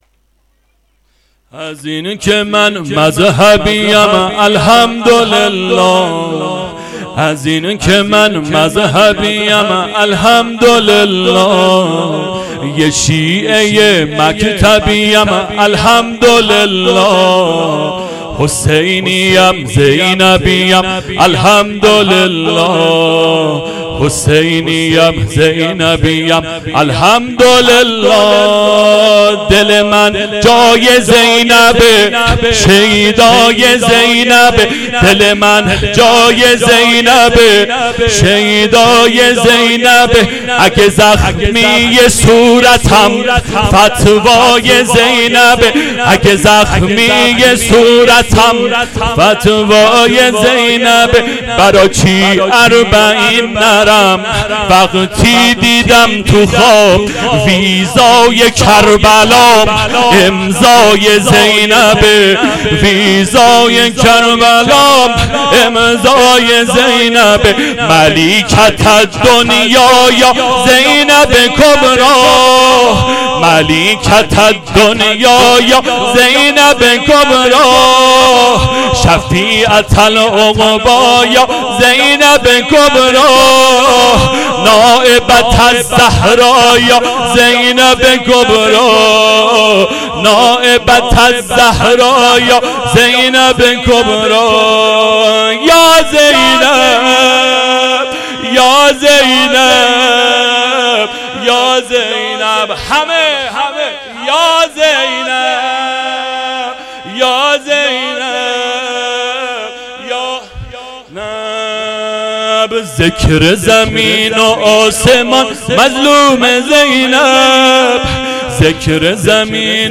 نوحه واحد طوفانی شب چهارم محرم ۹۷